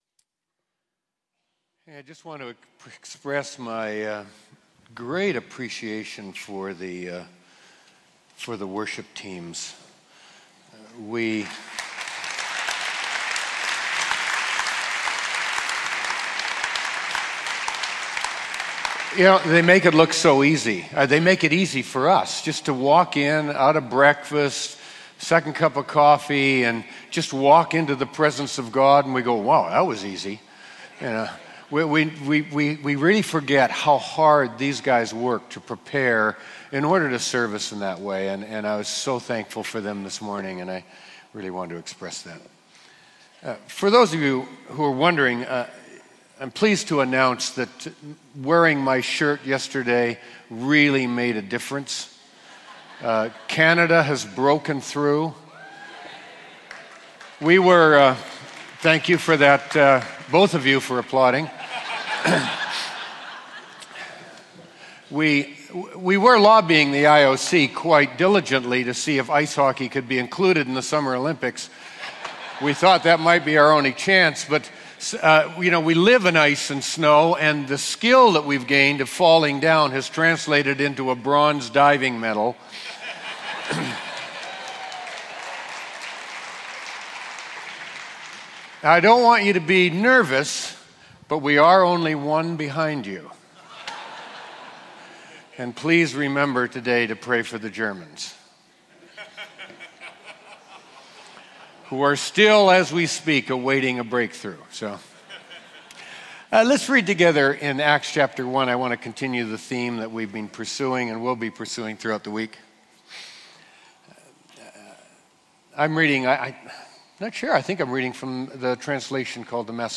New Wine Morning Bible Teaching Day 2 | St John's with St Mary's Mansfield
Teaching from New Wine Christian Conference – for all to share.